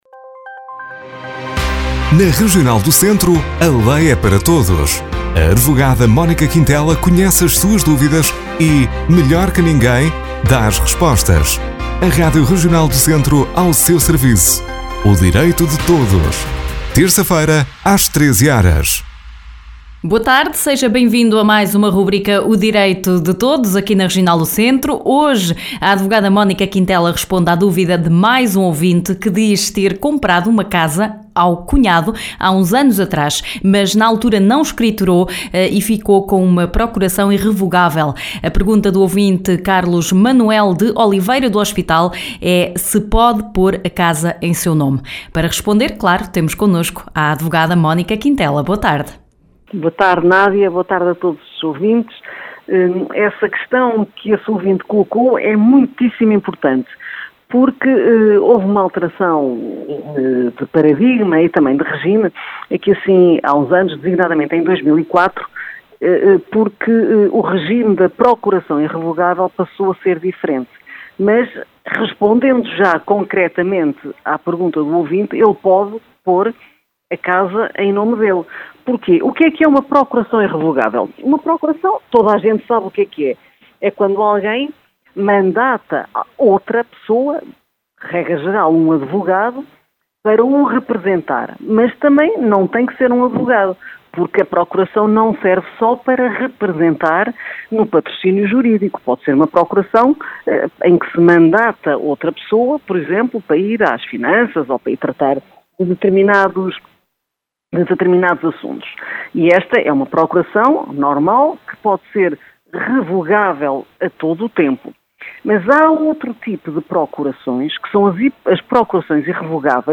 Hoje a advogada Mónica Quintela responde à duvida de mais um ouvinte que diz ter comprado uma casa ao cunhado há uns anos atrás mas não a escriturou na altura e ficou com uma Procuração irrevogável.